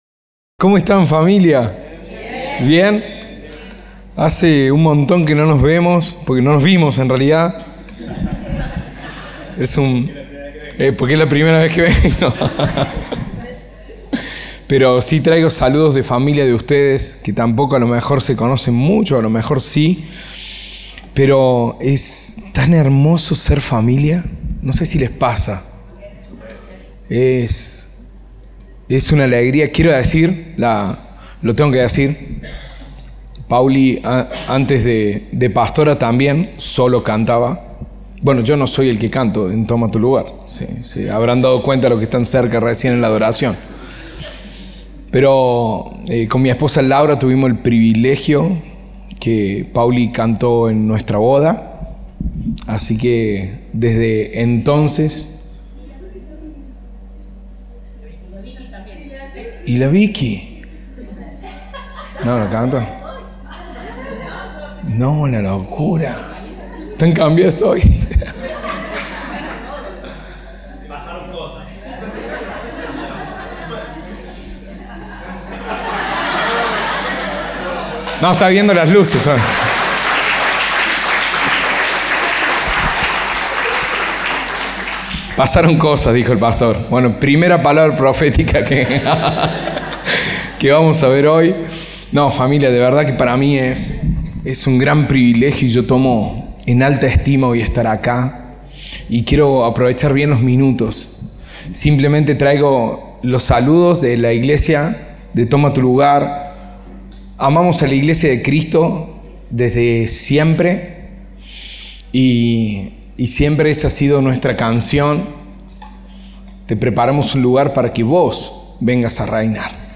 Compartimos el mensaje del Domingo 31 de Agosto de 2025 (10:00 hs)
Orador invitado